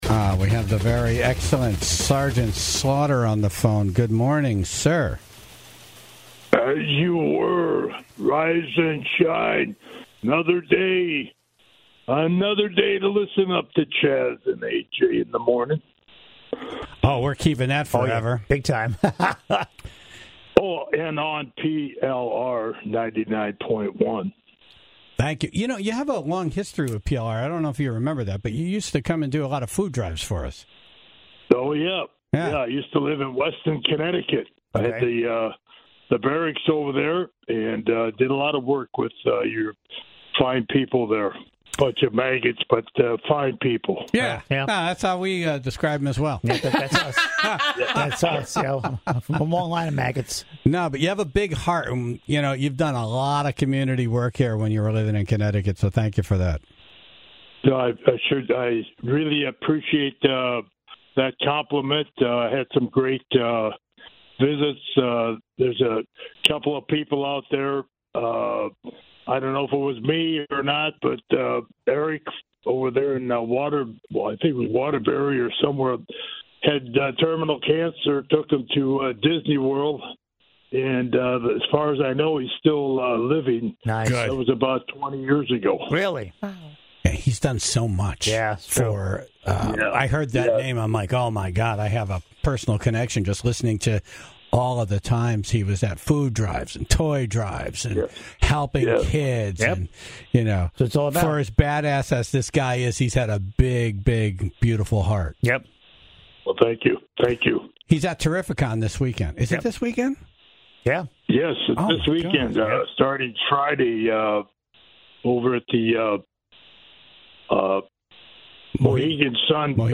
WWE Hall of Famer Sgt. Slaughter was on. He took calls live from the Tribe, shared how you could earn one of his coins by surviving a Cobra Clutch, and shared an unforgettable story about Andre the Giant pranking Haystacks Calhoun with Ex-Lax on a flight.